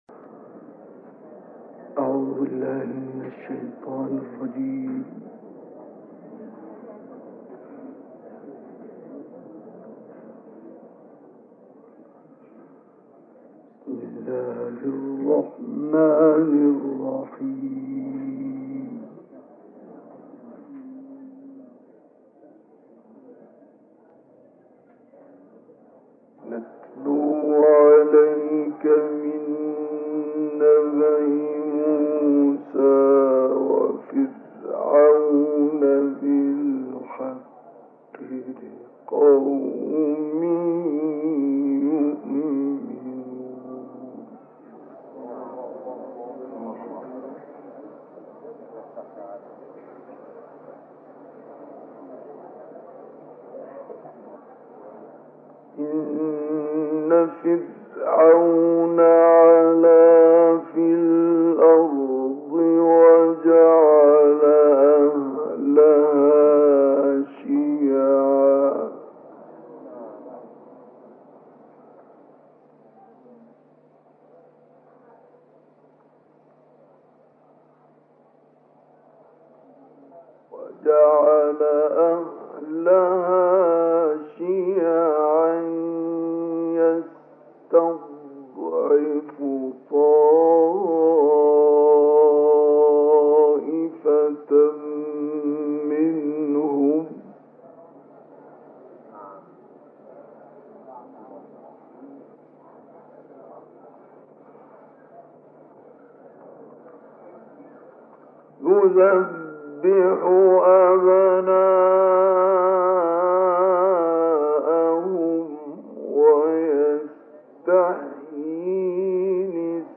القرآن الكريم - الكوثر: من أروع تلاوات القارئ الشيخ مصطفى اسماعيل تلاوة عطرة من سورة القصص (3-28) تلاها بمناسبة المولد النبوي الشريف عام 1964 في القاهرة.